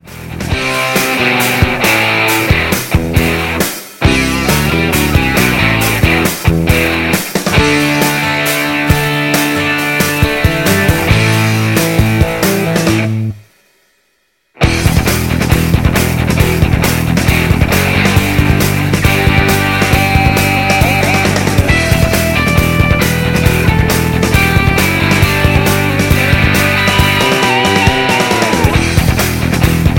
Bb
Backing track Karaoke
Rock, 1970s